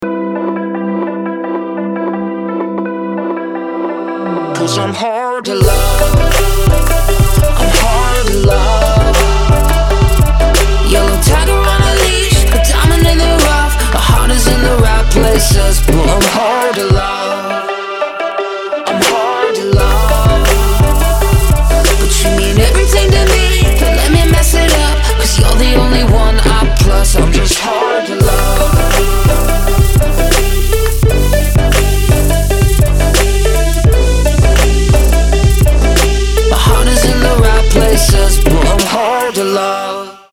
dance
Electronic
Electropop
vocal